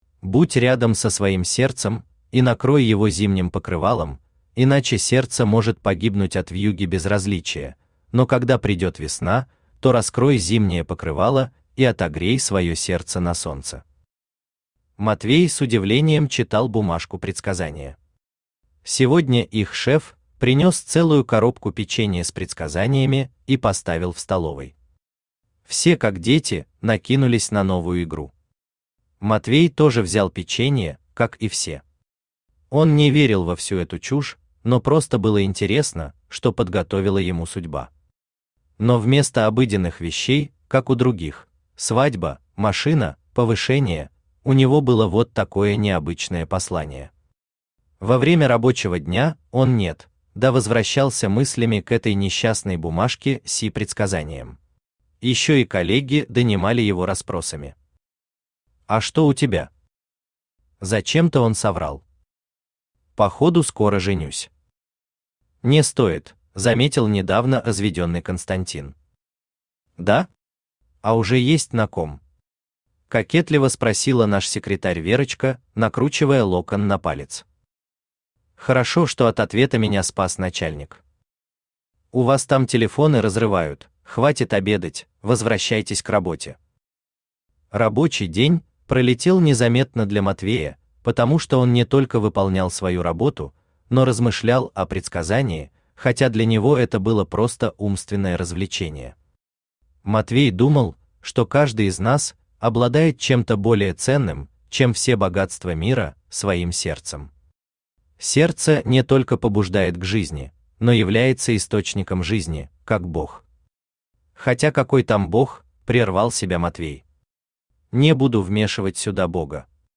Аудиокнига У Зимнего покрывала твоего сердца | Библиотека аудиокниг
Aудиокнига У Зимнего покрывала твоего сердца Автор Виталий Александрович Кириллов Читает аудиокнигу Авточтец ЛитРес.